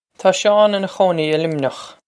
Pronunciation for how to say
Taw Shawn inna khoh-nee ih Lim-nyokh.
This is an approximate phonetic pronunciation of the phrase.